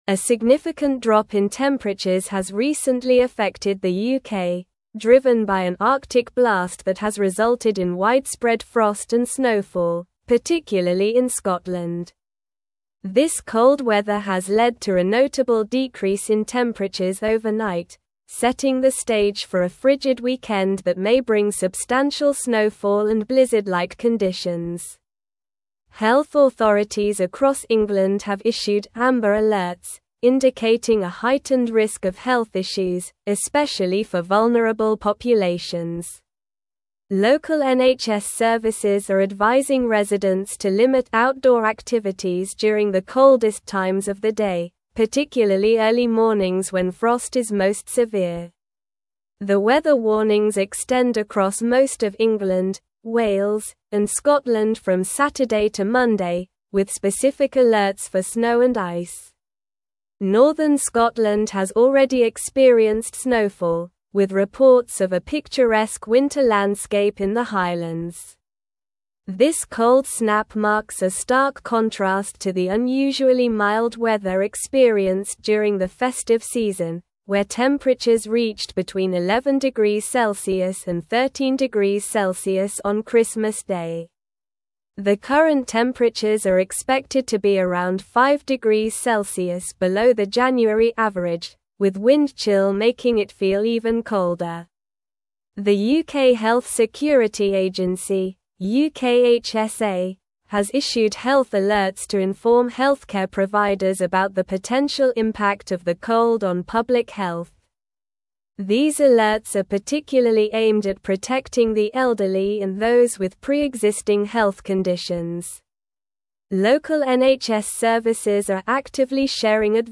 Slow
English-Newsroom-Advanced-SLOW-Reading-UK-Faces-Bitter-Cold-and-Heavy-Snow-This-Weekend.mp3